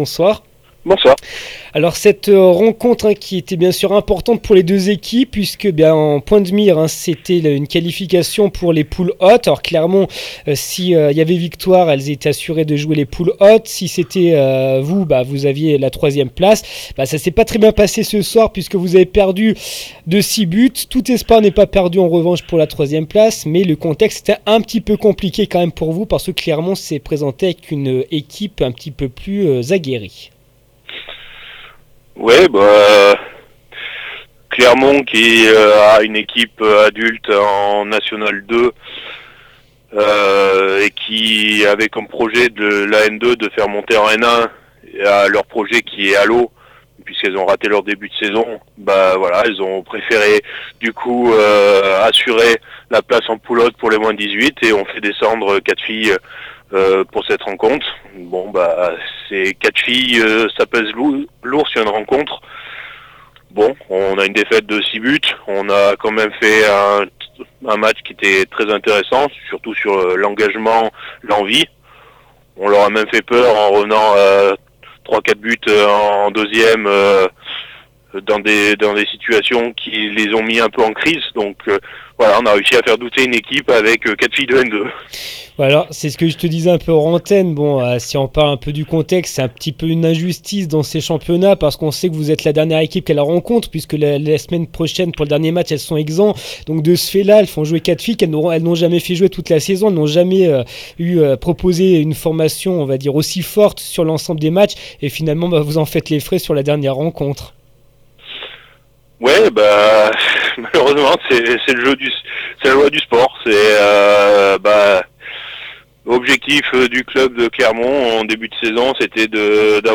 MATCH U18F HAND BALL ST GERMAIN BLAVOZY HOPC-CLERMONT 051215 REACTION APRES MATCH